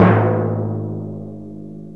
TIMP 2.WAV